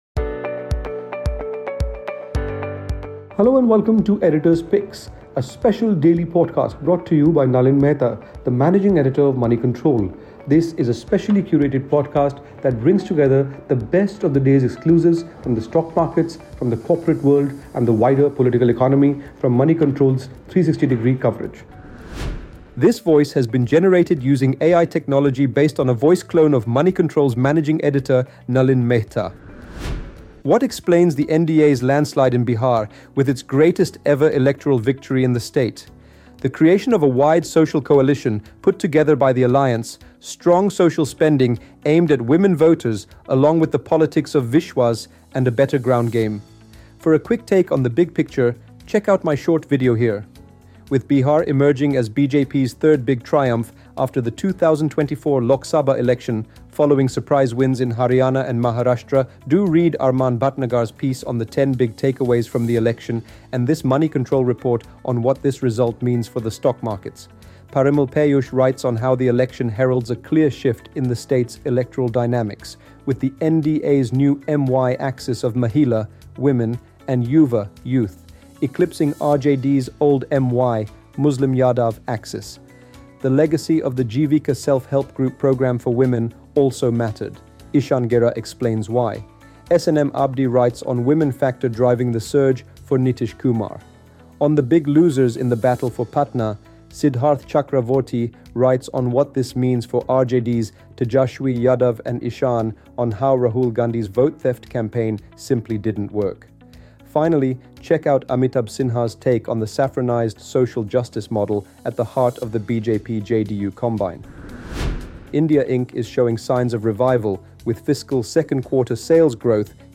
Tune in to this edition of Moneycontrol Editor’s Picks for our in-depth coverage of the Bihar election results - from the big picture view to what resonated with voters, the new M-Y axis and takeaways for the losers. Also find our coverage from policy, to markets, to corporate deals, including an interview with Tata Steel’s Managing Director TV Narendran who weighs in on India’s manufacturing capability.